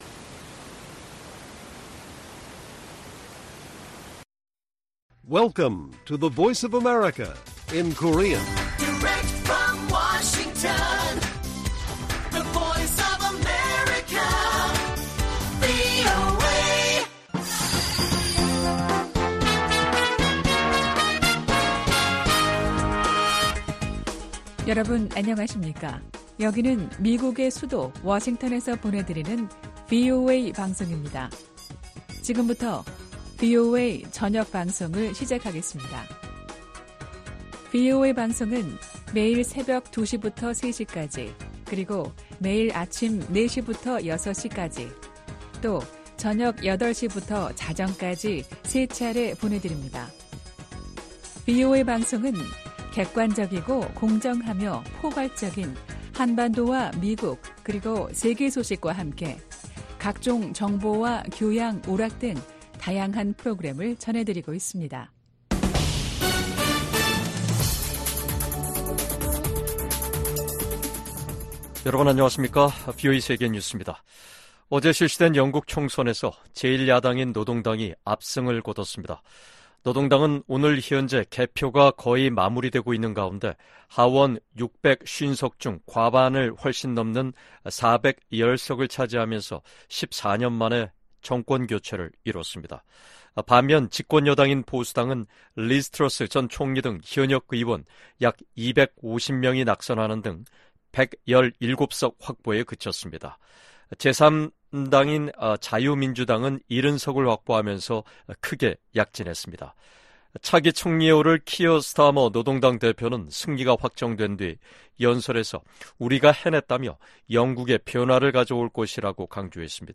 VOA 한국어 간판 뉴스 프로그램 '뉴스 투데이', 2024년 7월 5일 1부 방송입니다. 미 국무부는 북한 탄도미사일이 러시아의 우크라이나 공격에 사용됐다는 분석을 제시하며, 양국에 책임을 묻겠다고 경고했습니다. 북한을 비롯한 적성국들이 미국의 첨단 기술을 무단으로 사용하고 있는지 파악해 대응하도록 하는 법안이 미 하원에서 발의됐습니다.